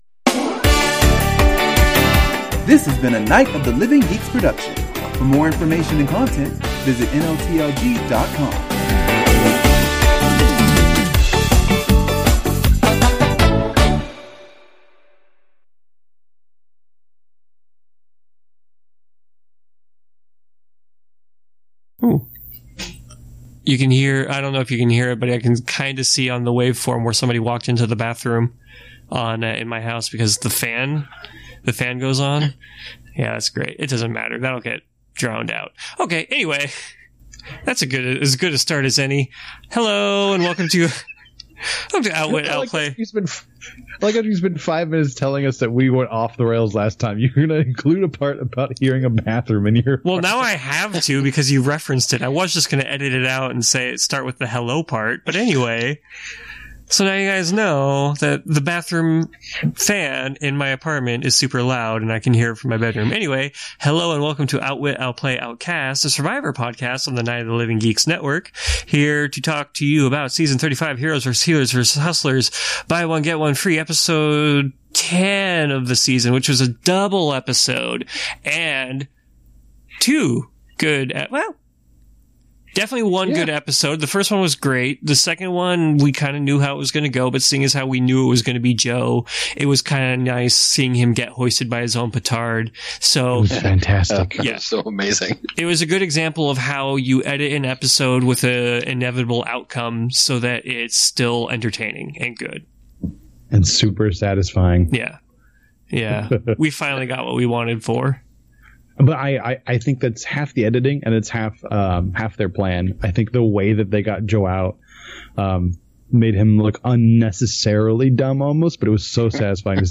We gather around the Skype fireside once again to make bold claims which occasionally turn out to be right, but quite often are wrong. Today we go so far as to check the tape in real time and finally find out who is wrong this time (HINT: It’s in the title).